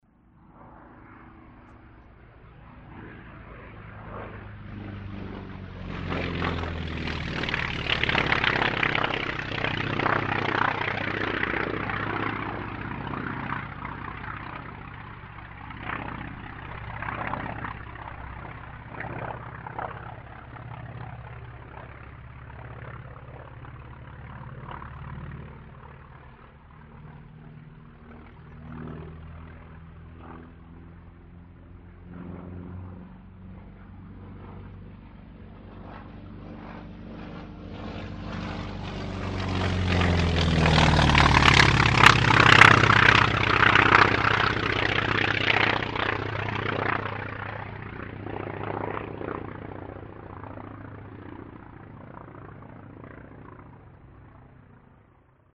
North American T-28 Trojan Photographed at Colorado Airshow, August 15, 2015.
The North American Aviation T-28 Trojan is a piston-engined military trainer aircraft used by the United States Air Force and United States Navy beginning in the 1950s.
T-28TrojanTrimed.mp3